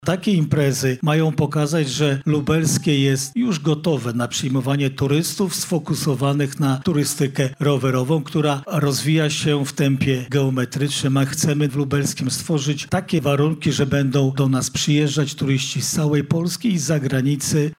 -mówi Jarosław Stawiarski, Marszałek Województwa Lubelskiego.